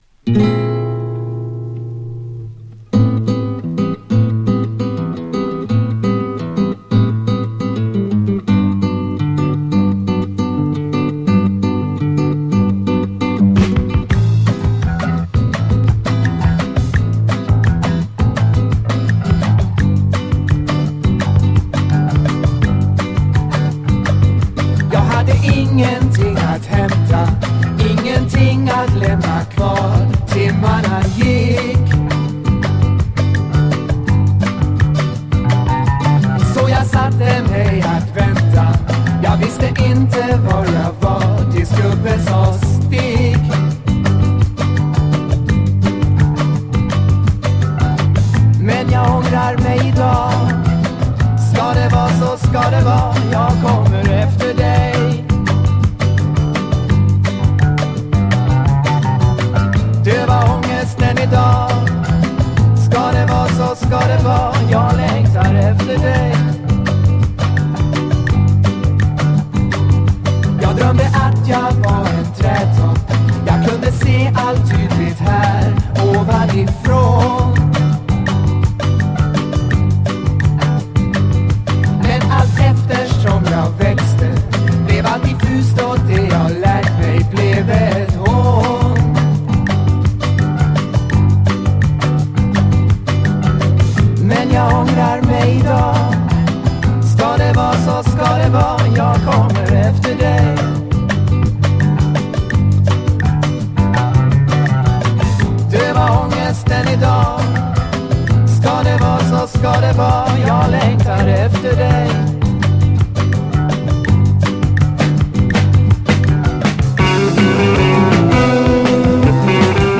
スウェーデンの5人組プログレ・バンド